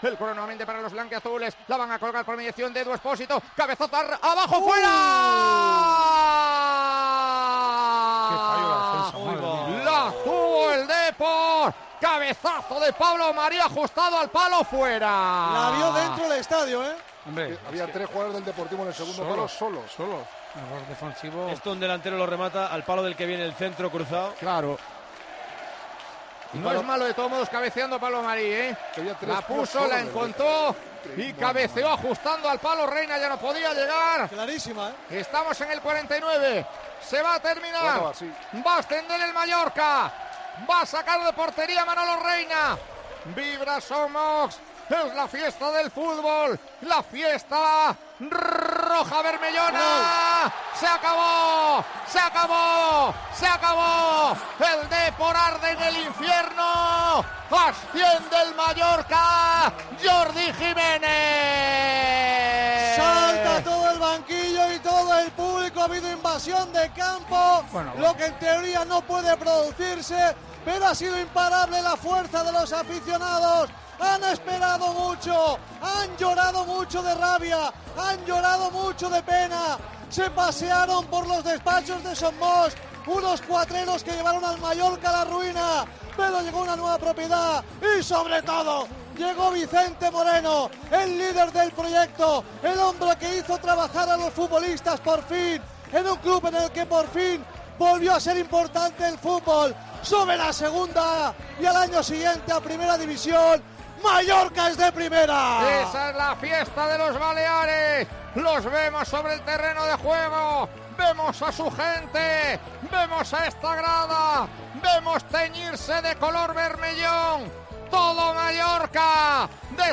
Así vivimos en Tiempo de Juego el ascenso del Mallorca a Primera División